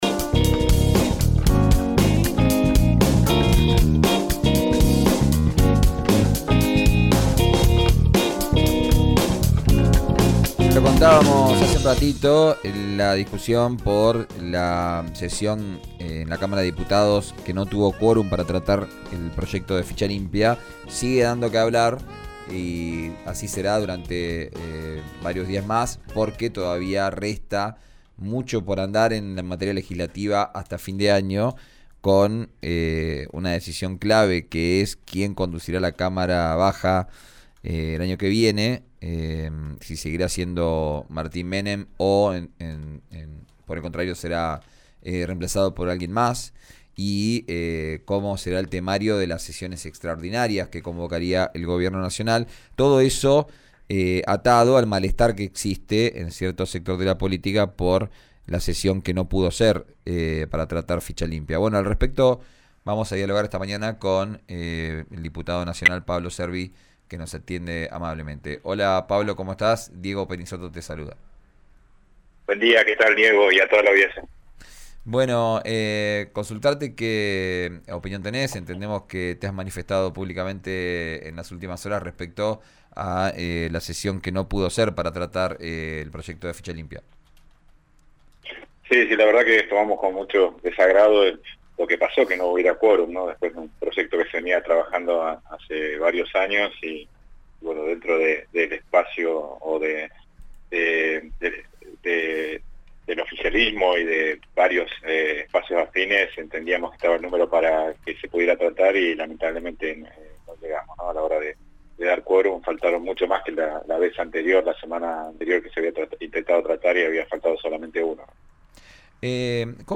Escuchá al diputado nacional Pablo Cervi , en RADIO RÍO NEGRO: